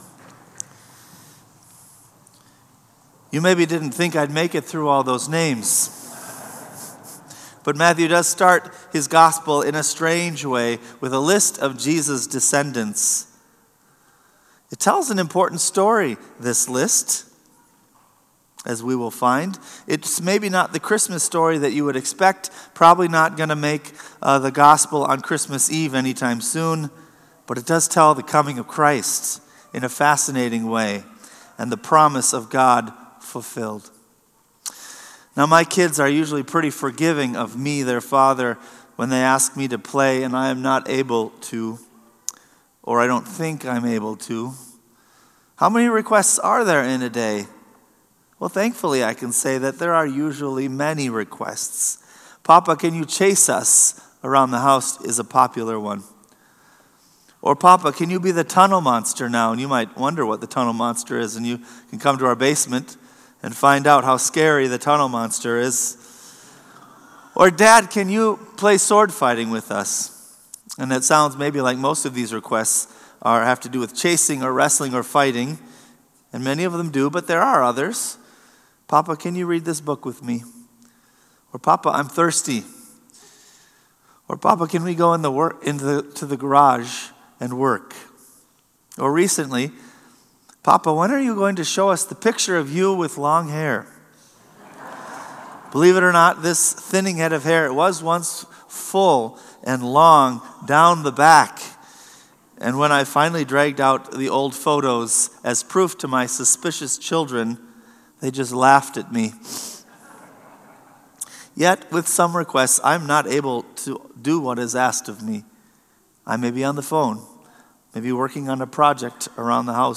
Sermon “A Promise Kept”